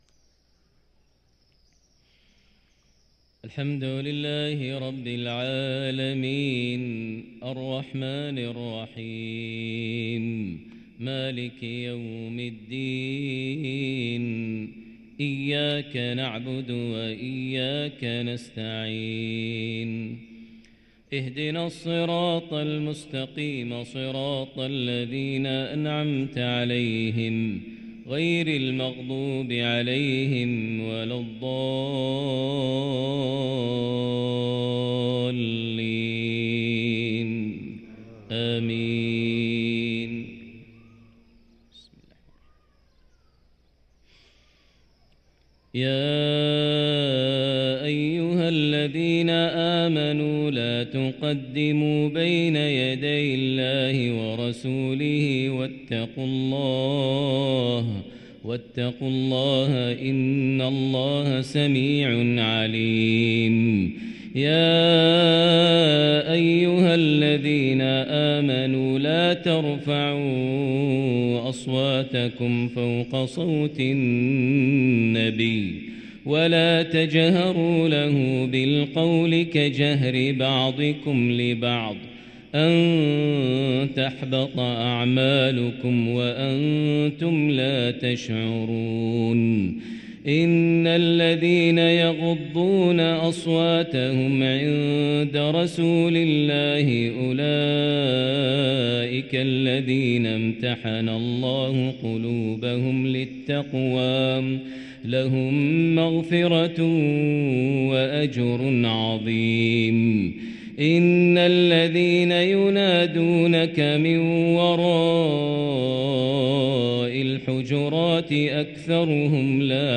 صلاة الفجر للقارئ ماهر المعيقلي 19 شعبان 1444 هـ